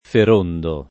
vai all'elenco alfabetico delle voci ingrandisci il carattere 100% rimpicciolisci il carattere stampa invia tramite posta elettronica codividi su Facebook Ferondo [ fer 1 ndo ] pers. m. — personaggio del Boccaccio